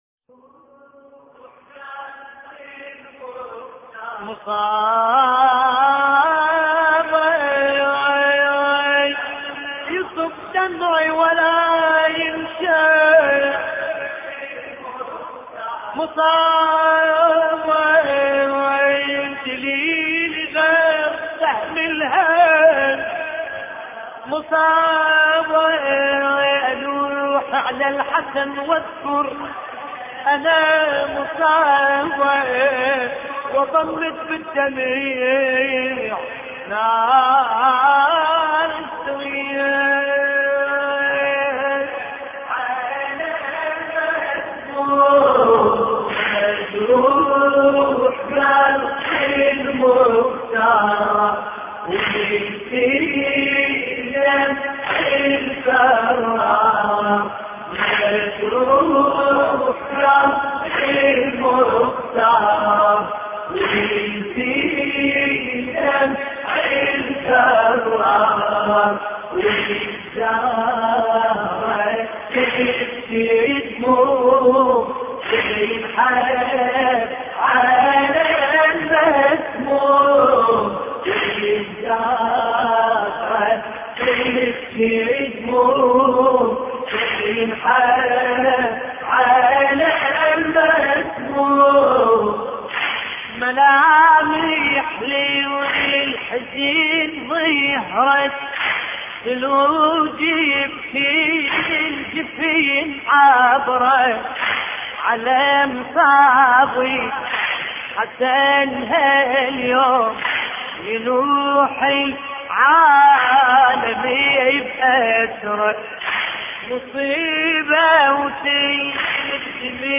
مراثي الامام الحسن (ع)